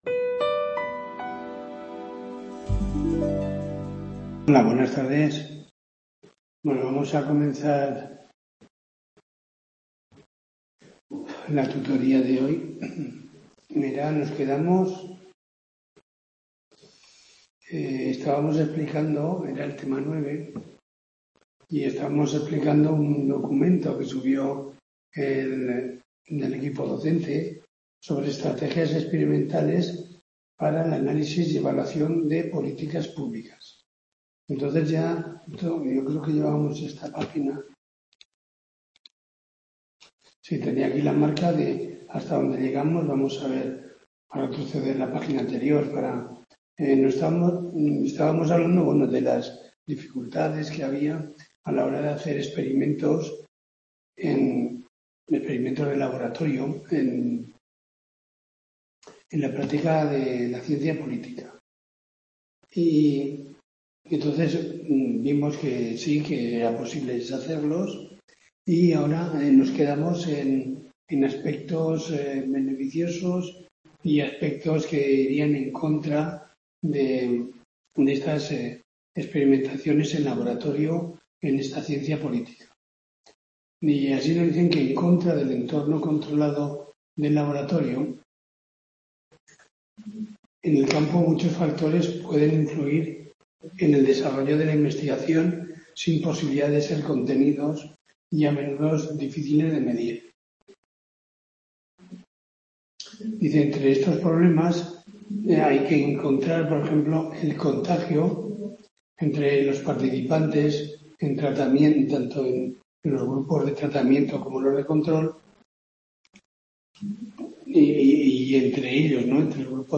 TUTORIA